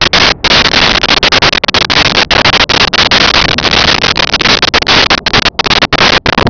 Sfx Thunder 09
sfx_thunder_09.wav